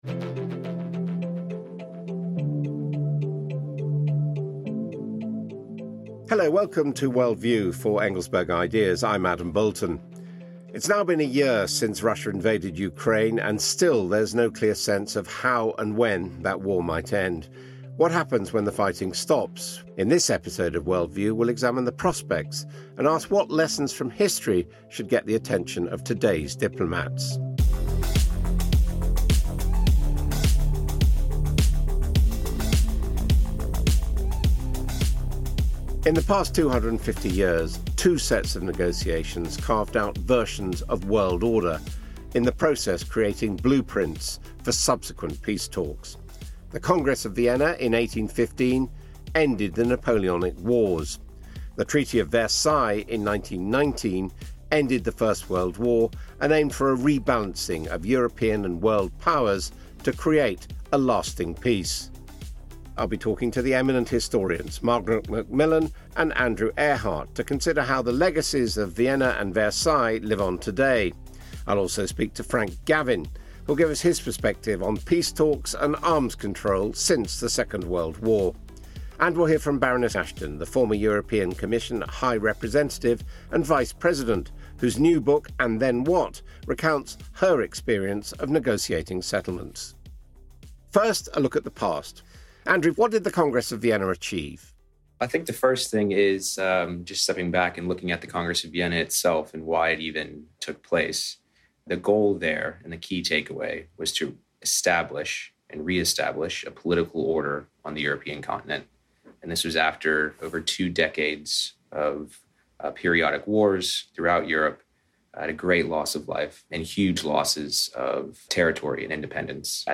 In our latest episode of Worldview, host Adam Boulton is joined by historians Margaret MacMillan